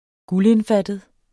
Udtale [ ˈgulenˌfadəð ]